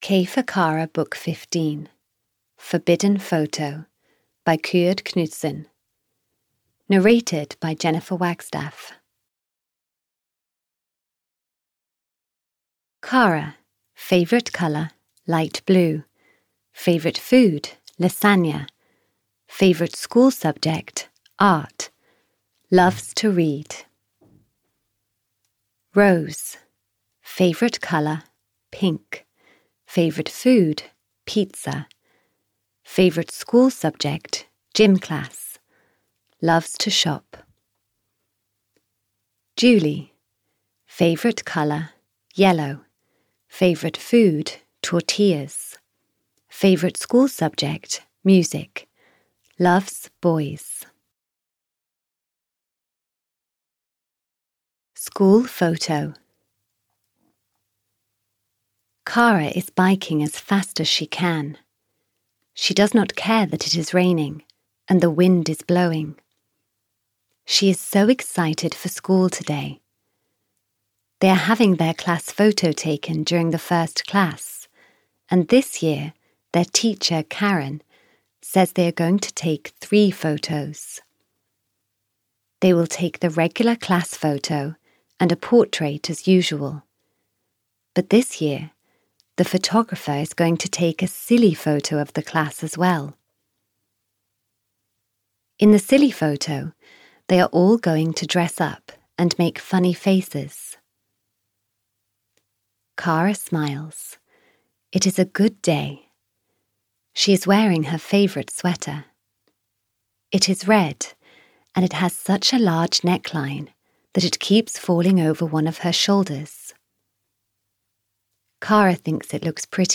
Audio knihaK for Kara 15 - Forbidden Photo (EN)
Ukázka z knihy